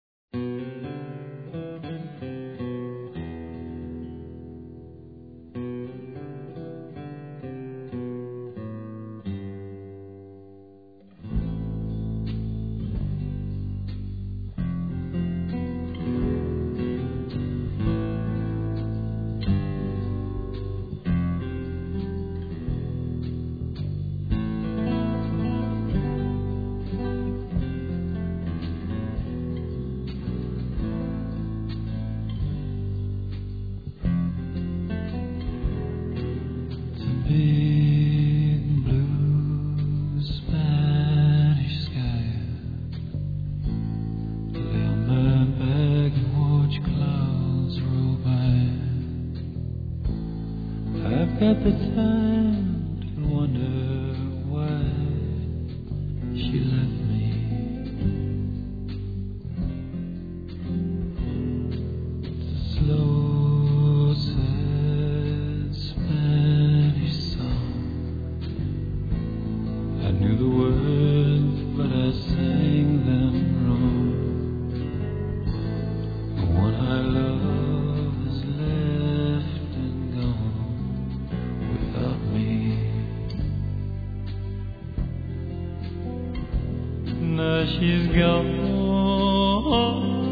Ecoutez bien cette voix.